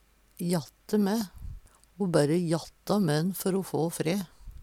jatte mæ - Numedalsmål (en-US)